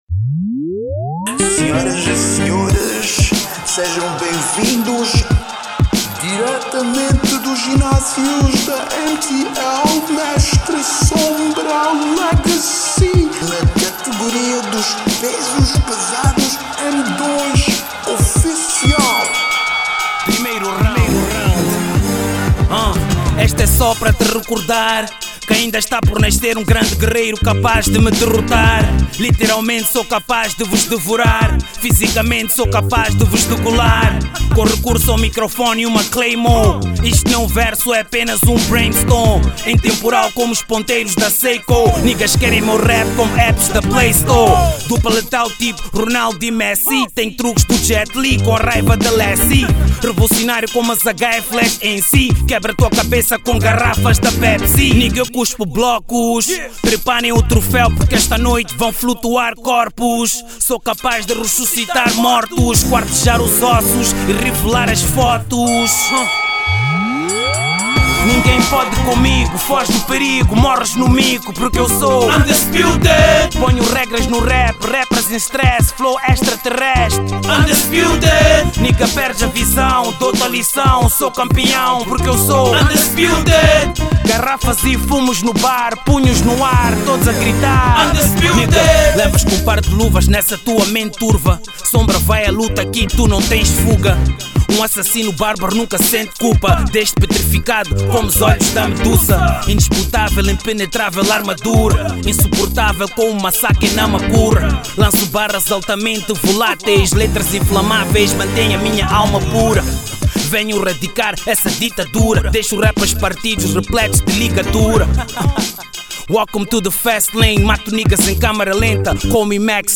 Género: Hip-hop/Rap